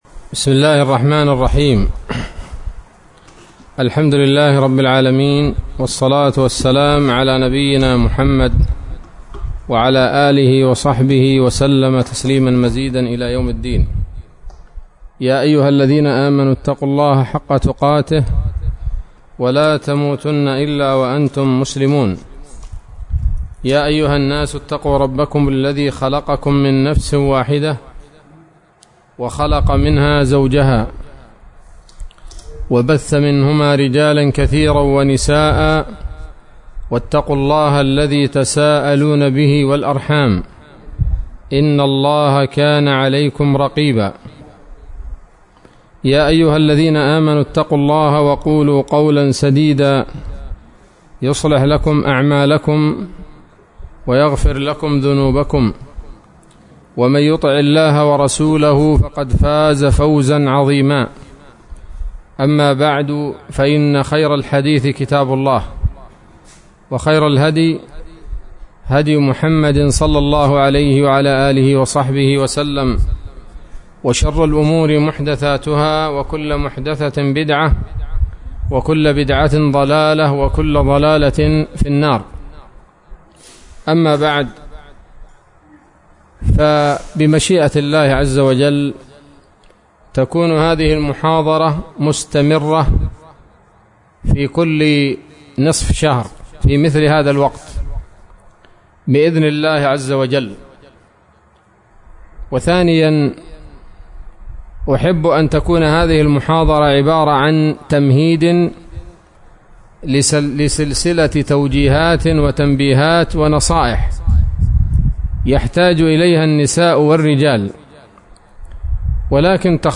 محاضرة قيمة للنساء بعنوان
عصر الخميس 20 صفر 1447هـ، بدار الحديث السلفية بصلاح الدين